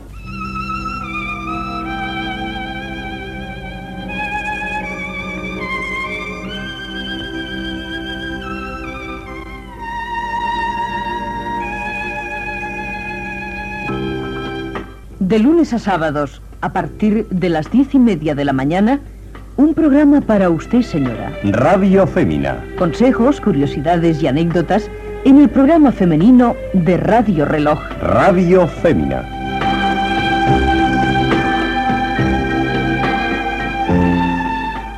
Promoció del programa.